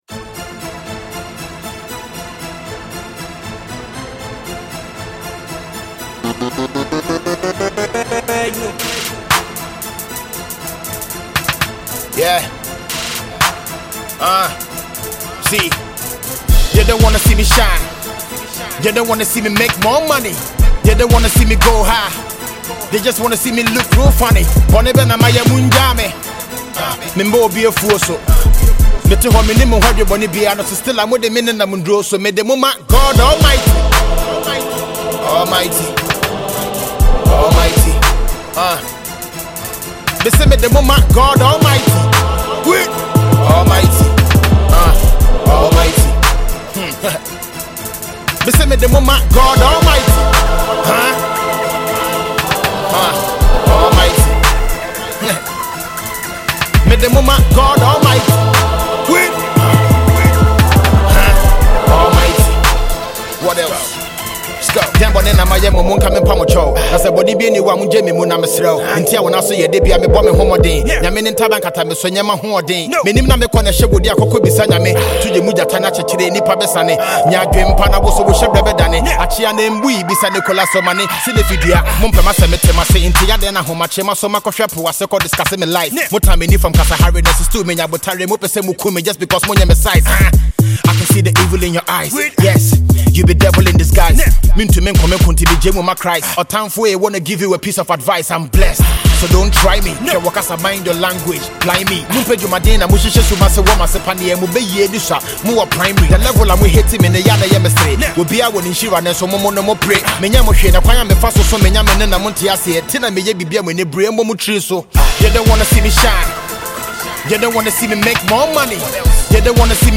This one is a thankful hip-hop joint